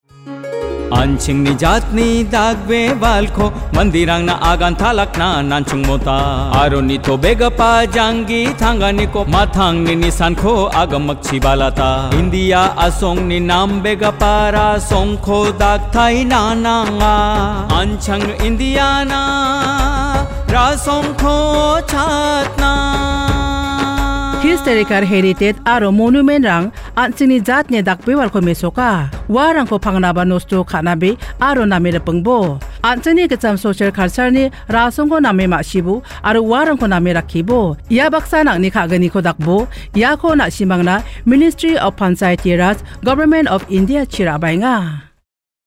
52 Fundamental Duty 6th Fundamental Duty Preserve composite culture Radio Jingle Garo